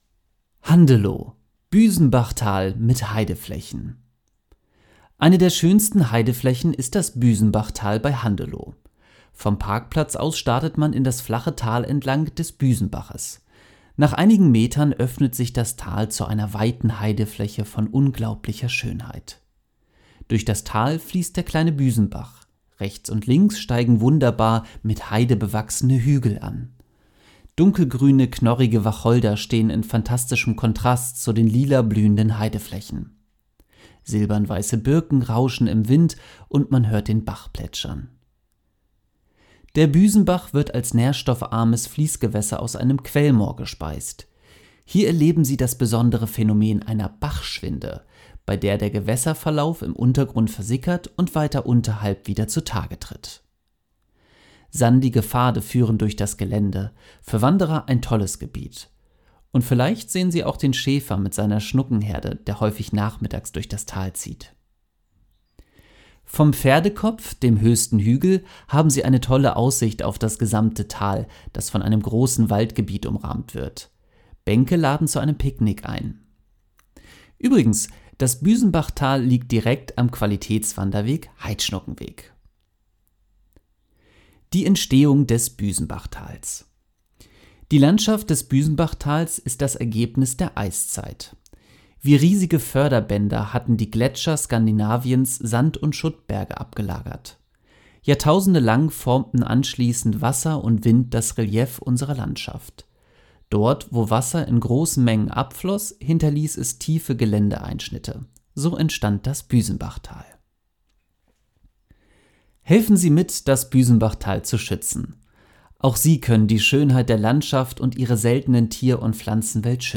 b-senbachtal-text-vorlesen-lassen.mp3